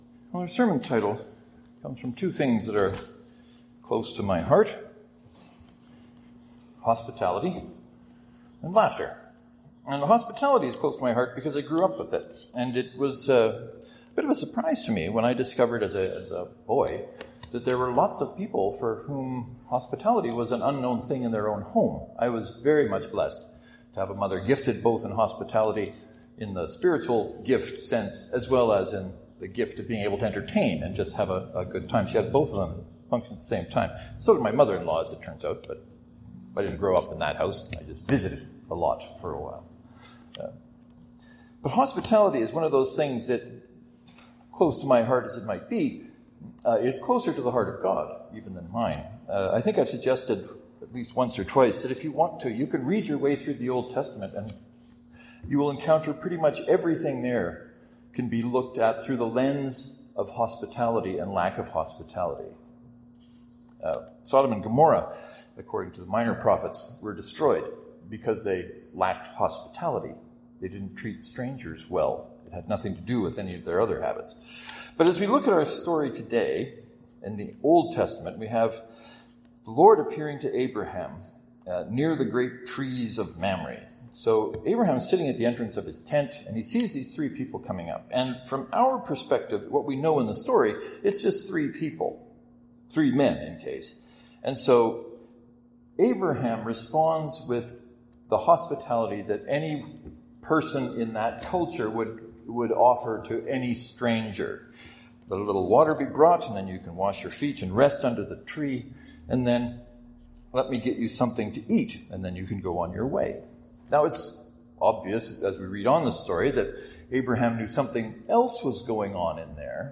As I was getting ready for this Sunday’s sermon I found myself going back again to the Old Testament lesson. In this story we see Abraham providing hospitality, with Sarah’s help of course, and Sarah laughing.